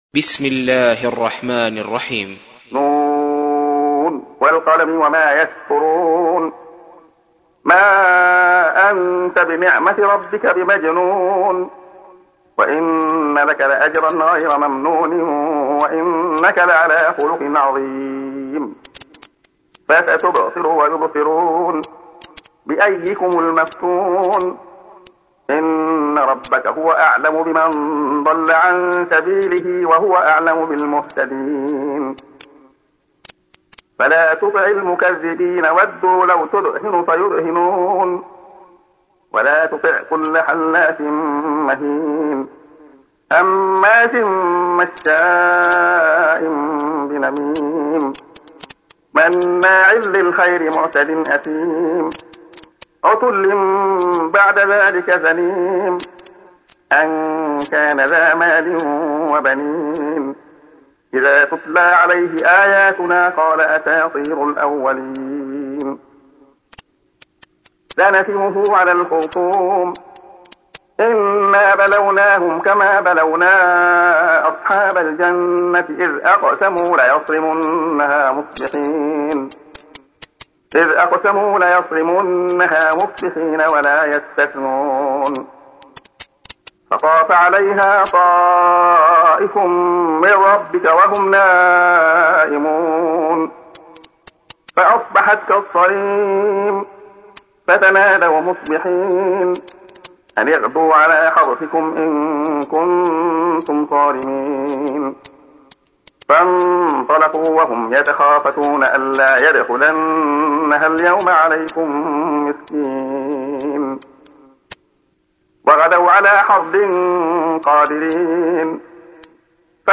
تحميل : 68. سورة القلم / القارئ عبد الله خياط / القرآن الكريم / موقع يا حسين